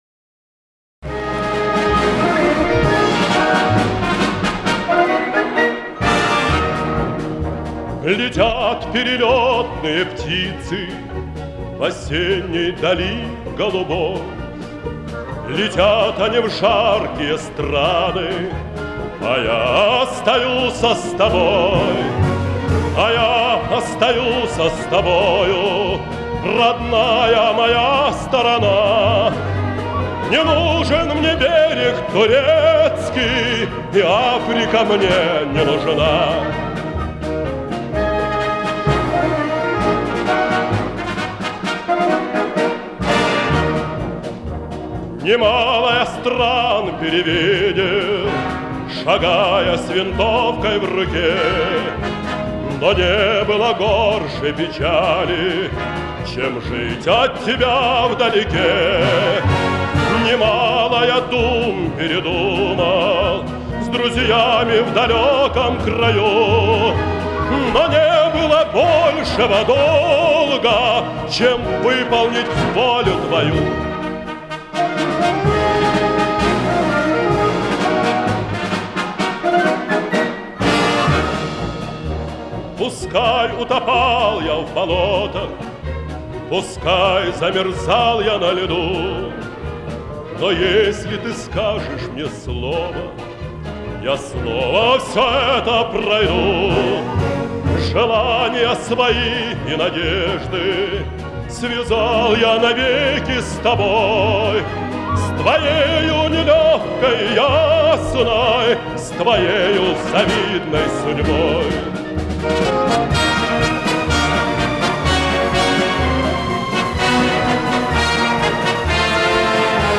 (концертная запись)